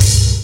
BWB HIT (13).wav